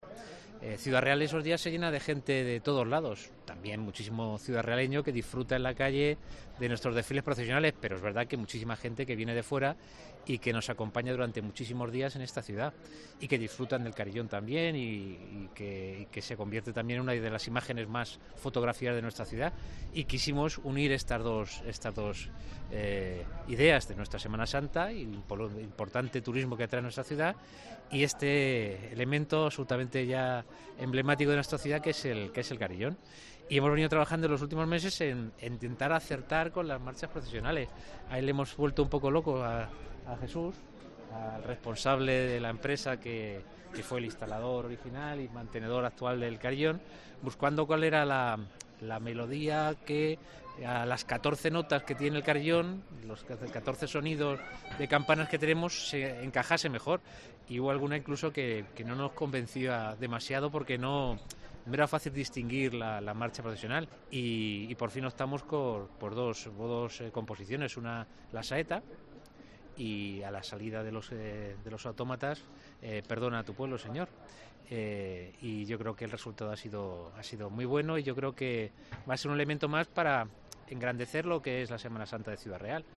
Paco Cañizares, alcalde de Ciudad Real